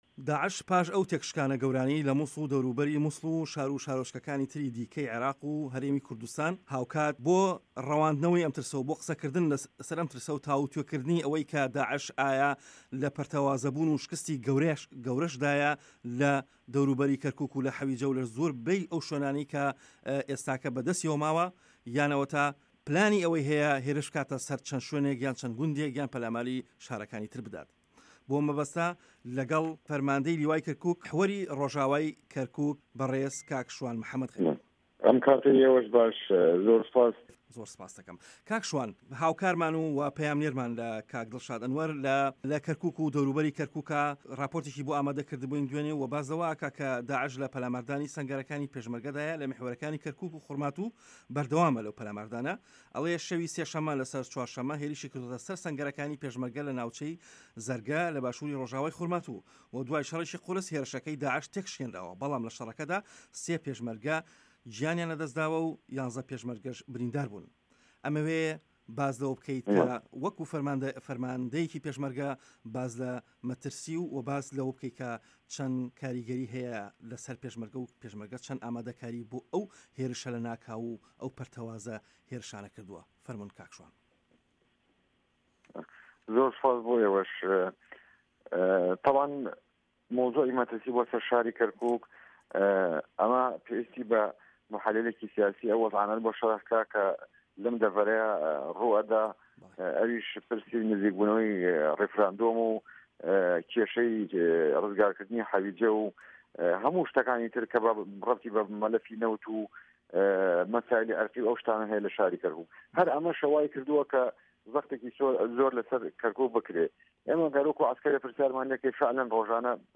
وتووێژ لەگەڵ لیوا شوان محەمەد غەریب